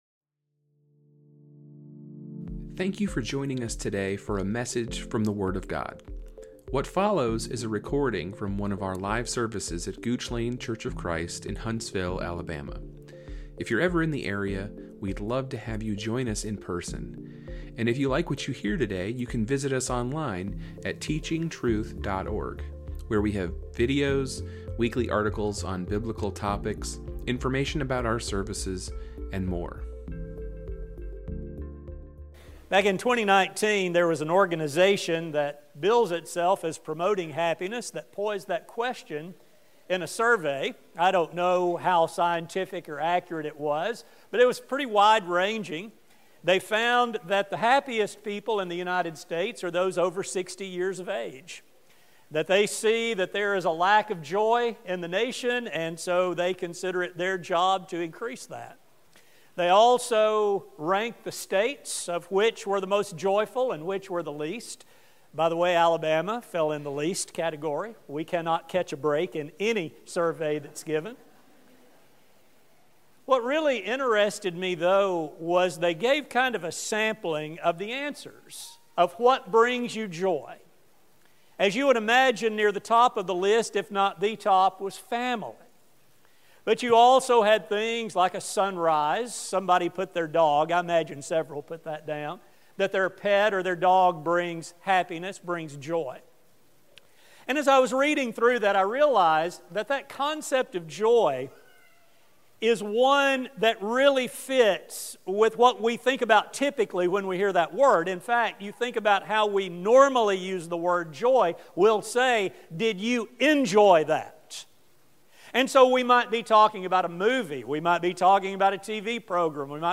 This sermon will explore this great opportunity given to God’s people. This study will focus on how this is done and the preparation necessary to be a fit vessel for this service.